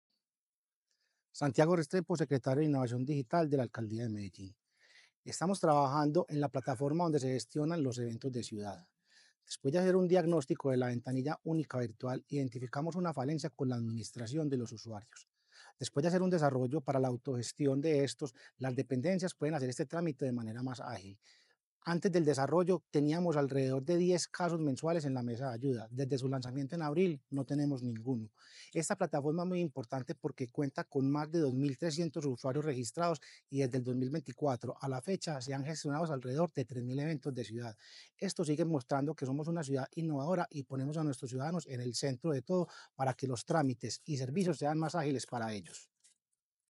Audio-Declaraciones-del-secretario-de-Innovacion-Digital-Santiago-Restrepo-Arroyave-2.mp3